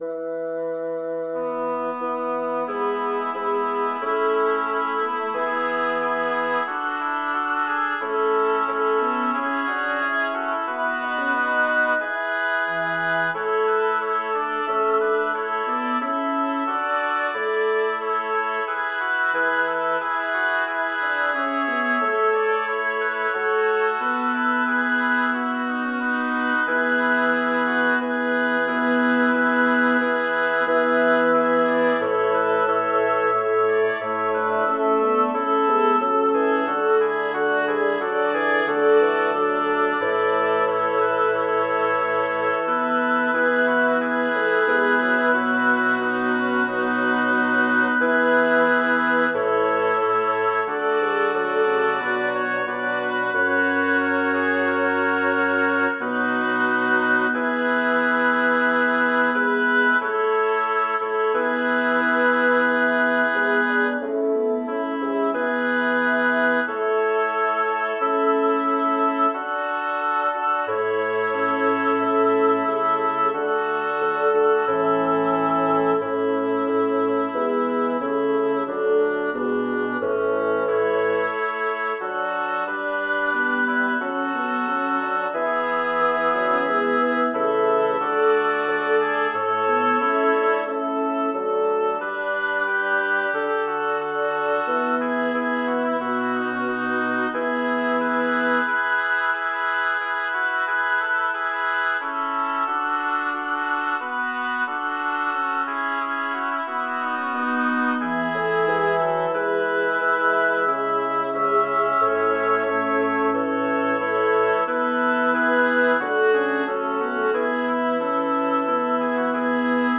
Title: Accedentes servi Composer: Melchior Vulpius Lyricist: Number of voices: 6vv Voicing: SSATTB Genre: Sacred, Motet
Language: Latin Instruments: A cappella
First published: 1602 in Cantionum sacrarum prima pars, no. 15 Manuscript 1592-1685 (copied 1612-1618) in Löbau partbooks, no. 64 Description: The parable of the Sower from Matthew 13, 27 - 30. Second part ("cantilena") is à 3 (SST).